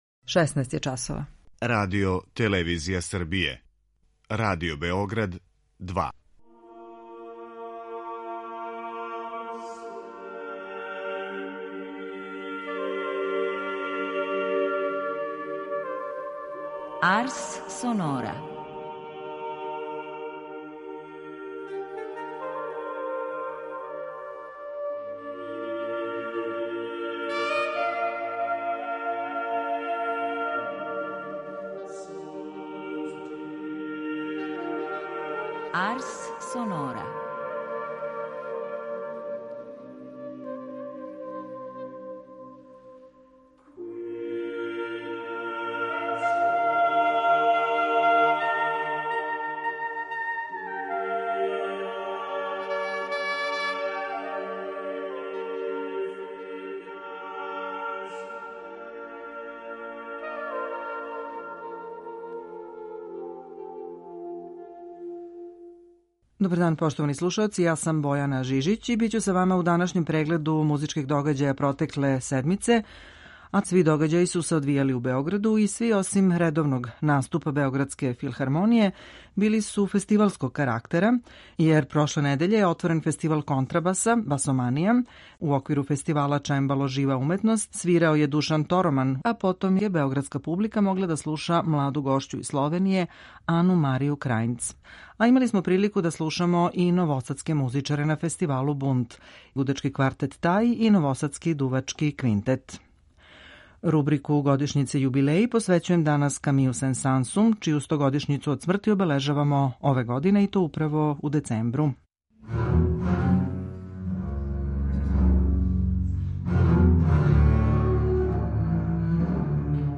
Група аутора Емисија посвећена најважнијим музичким догађајима.